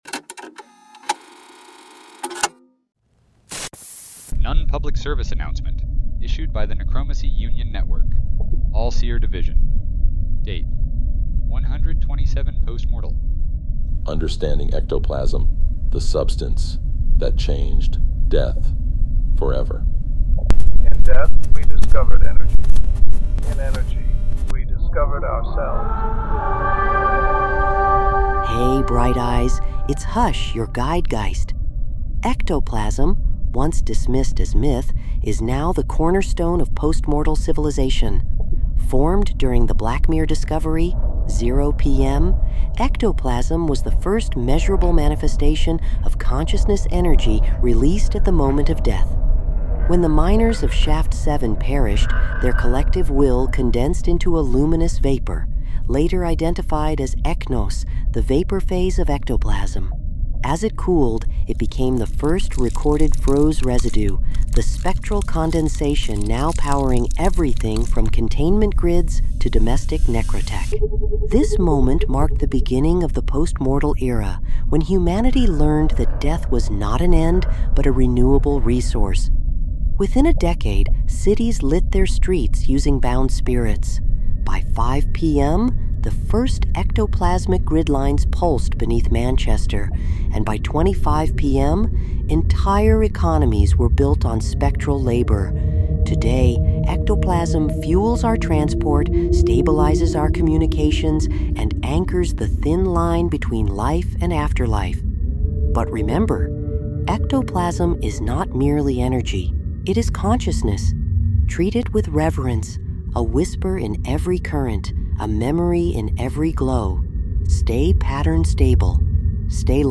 Al-Seer PSA about ectoplasm and the Blackmere discoveries.